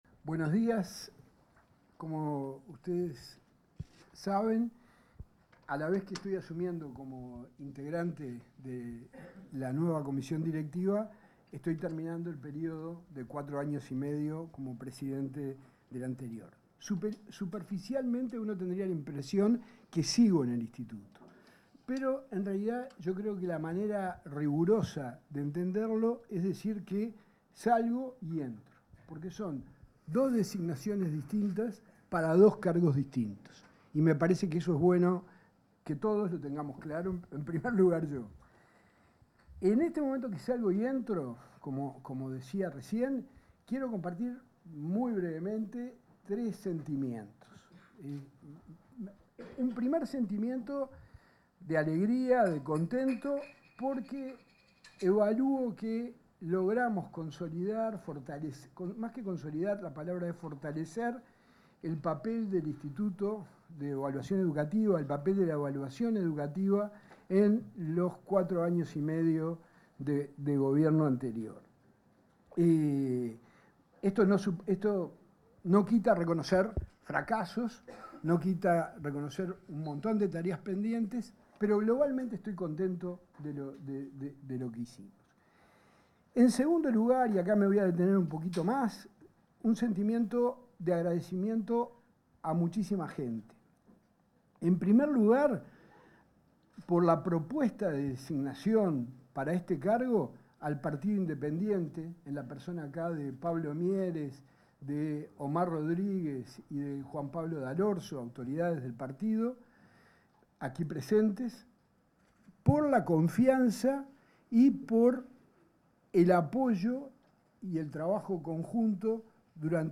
Palabras en la presentación de autoridades del Ineed 11/06/2025 Compartir Facebook X Copiar enlace WhatsApp LinkedIn En la presentación de la comisión directiva del Instituto Nacional de Evaluación Educativa (Ineed), se expresaron el ministro de Educación, José Carlos Mahía; el presidente de la Administración Nacional de Educación Pública, Pablo Caggiani, y las nuevas autoridades, Martín Pasturino, Celsa Puente y Javier Lasida.
oratorias.mp3